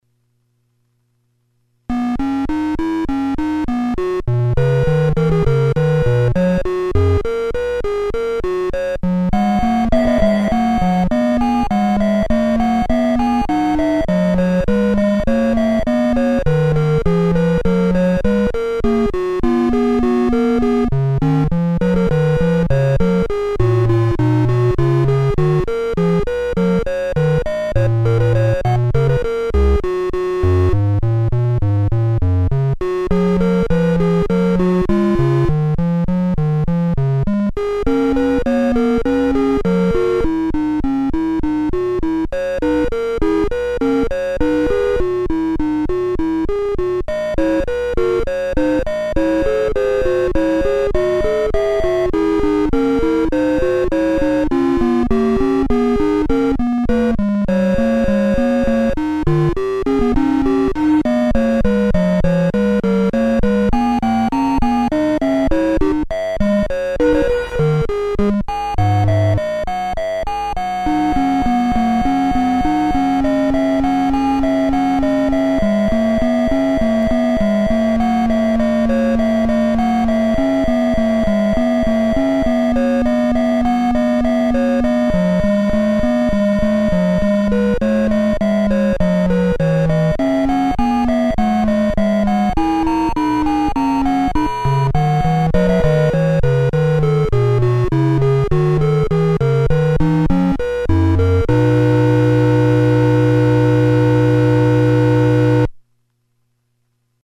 Here is an audio clip of J.S. Bach's Two Part Invention No. 1 on PDP-1.